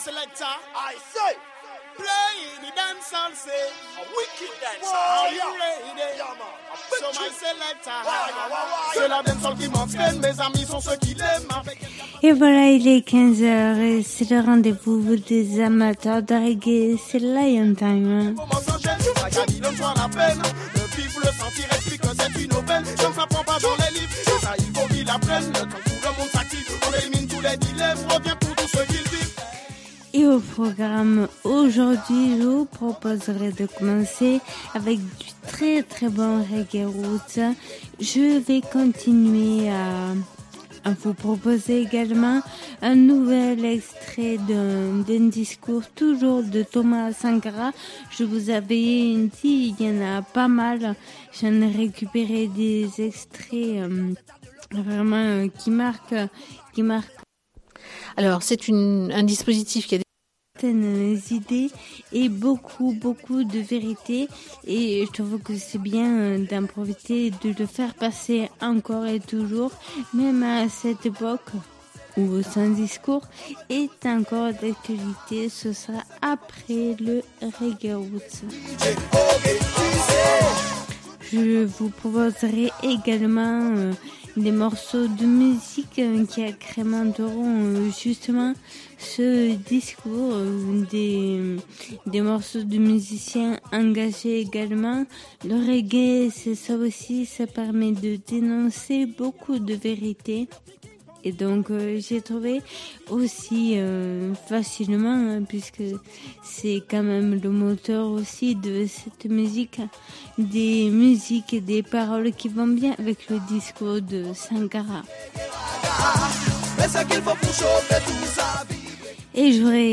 Émission qui invite au voyage et à la découverte du roots du reggae et du dancehall. Que vous soyez novices ou grands connaisseurs de la musique reggae et la culture rasta, cette émission permet d’écouter de la bonne musique et surtout des artistes de qualité qui sont très rarement programmés sur d’autres radios.